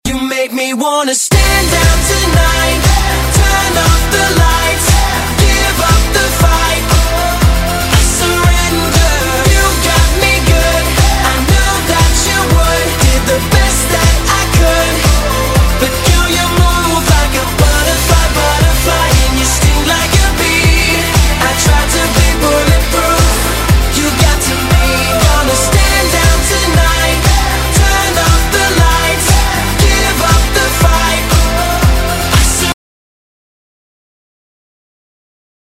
DubStep / Дабстеп